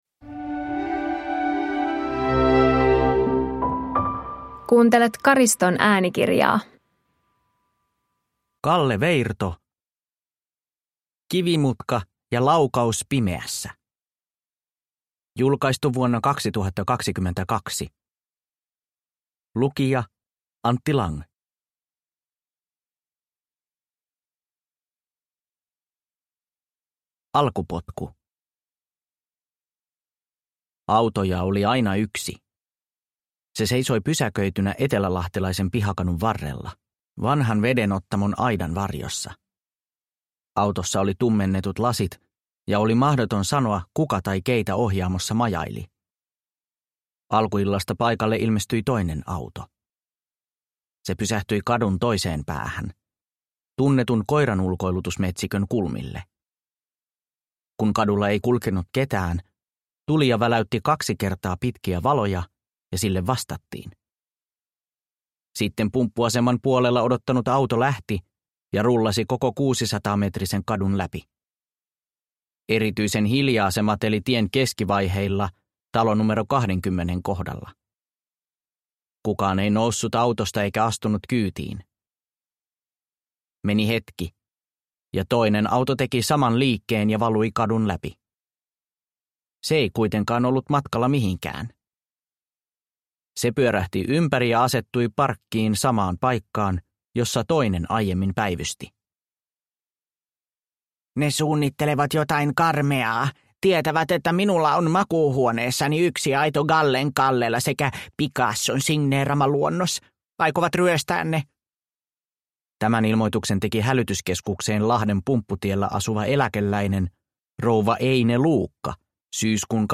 Kivimutka ja laukaus pimeässä – Ljudbok